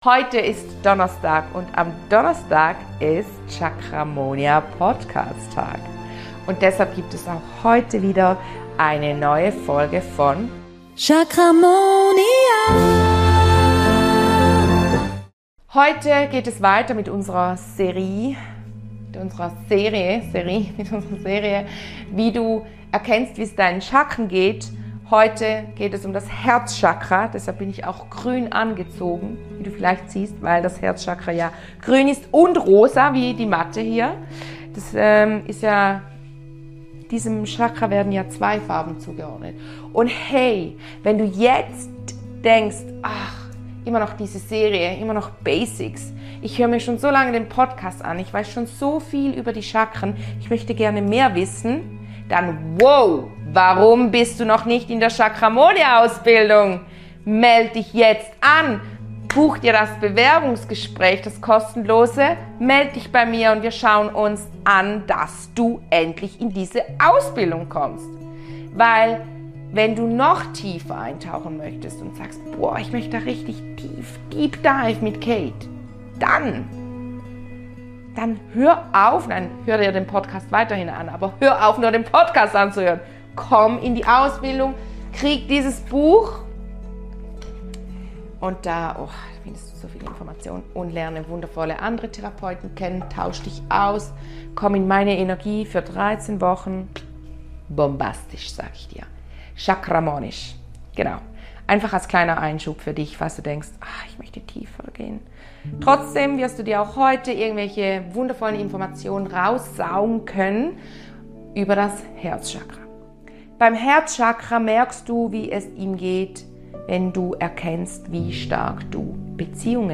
Unser viertes Chakra steht für bedingungslose Liebe und Lebensfreude. Gemeinsam werden wir ein Klangbad nehmen, um deine Verbindung zu deinem Herzchakra zu vertiefen und dich auf dem Weg zu Liebe und Freude zu unterstützen.